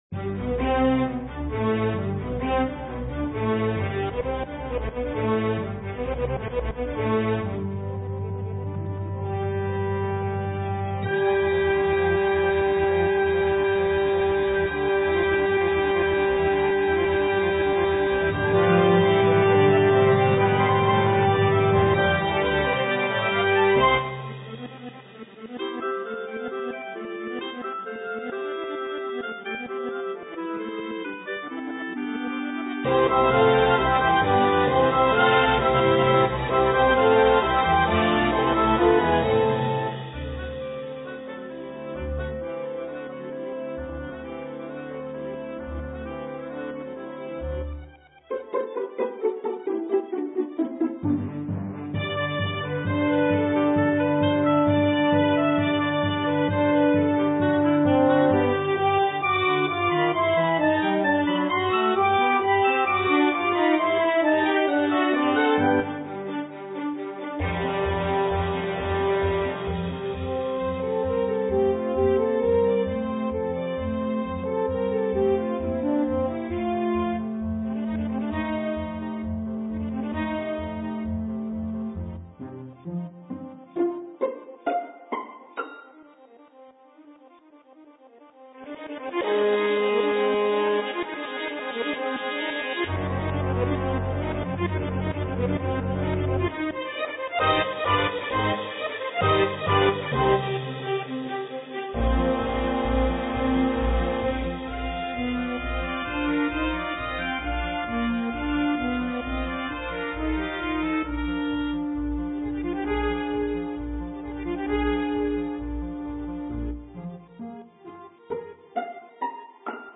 Piccolo, 2 Flutes, 2 Oboes, 2 Clarinets in Bb, 2 Bassoons
Strings (Violin 1, Violin 2, Viola, Cello, Bass)